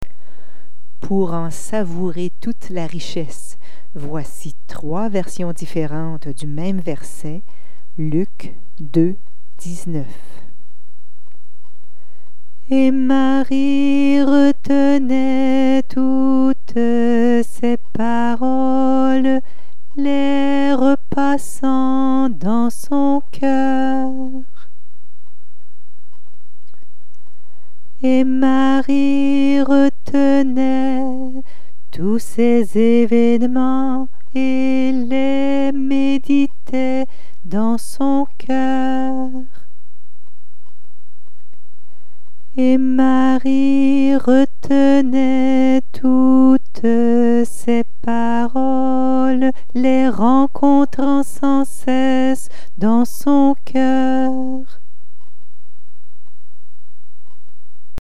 Versets chantés en récitatif